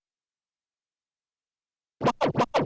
scratch_snippet